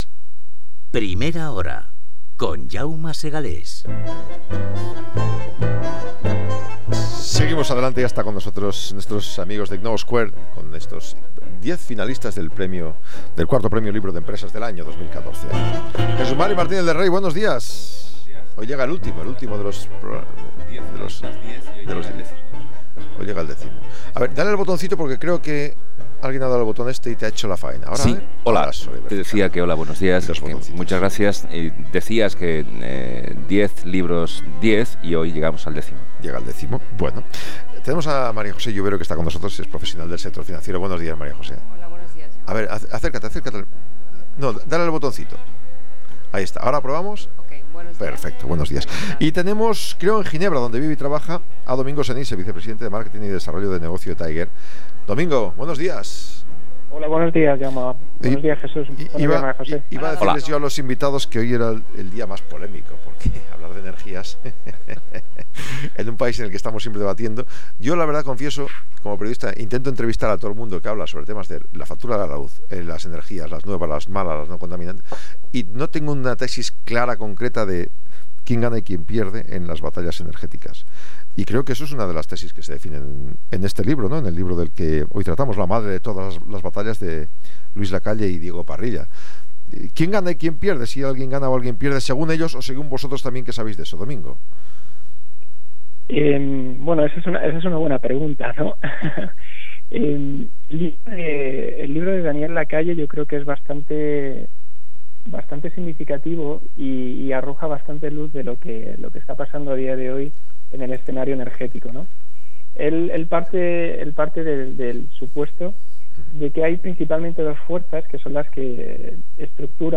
Entrevista en Gestiona Radio: La madre de todas las batallas